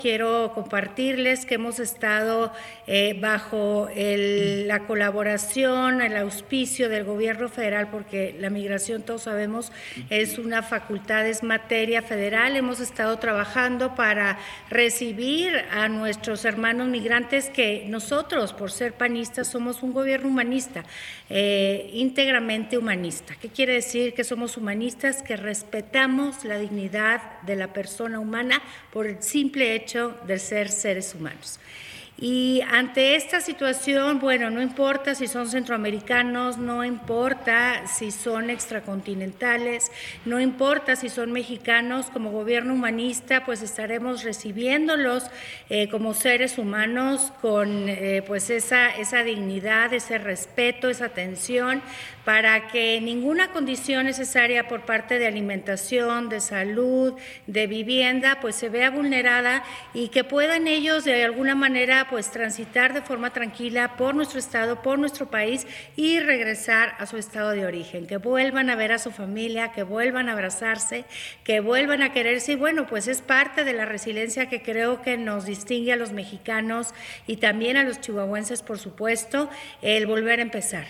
Durante una reunión virtual del Comité Ejecutivo Nacional del Partido Acción Nacional (PAN), la mandataria indicó que, en coordinación con el Gobierno Federal, brindará alimentación, salud e incluso vivienda a los connacionales, centro y sudamericanos que sean repatriados al lado Chihuahua de la frontera.